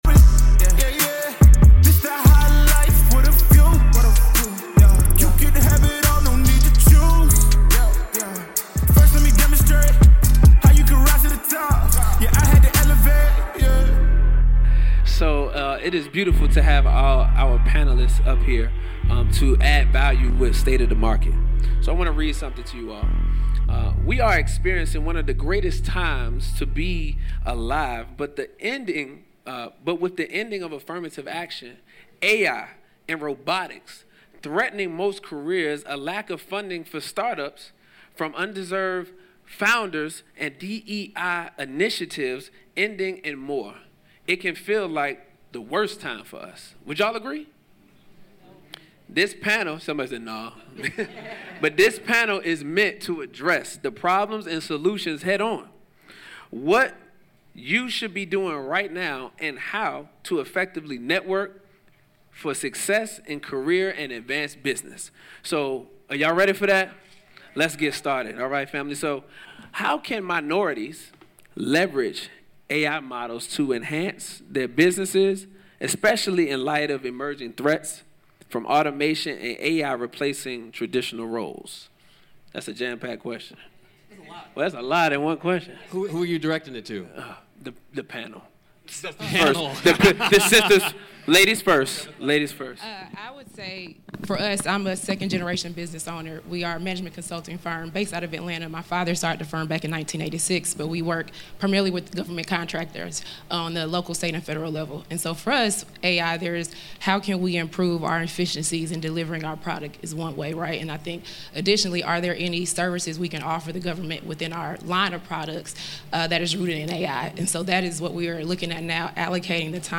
At Tech Is The New Black we interview dope people in tech who can give crazy insights into this industry.